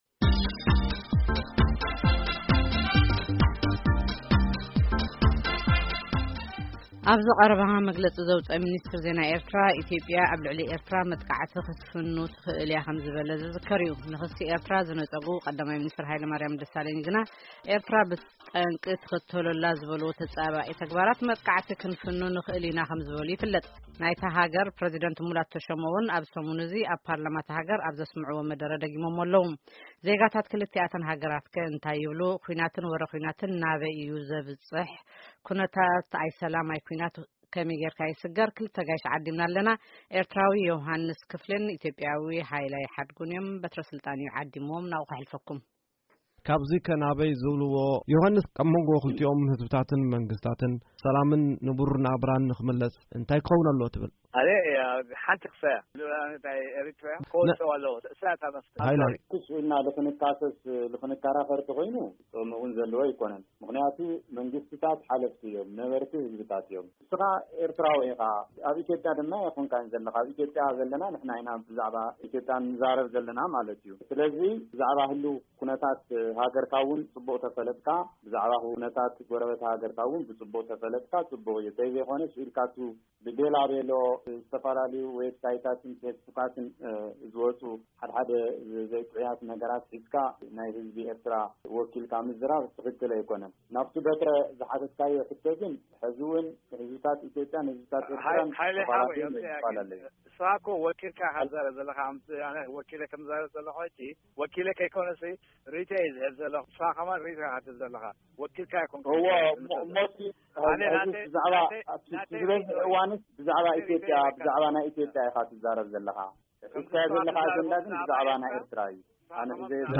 ክትዕ ክልተ ኣጋይሽ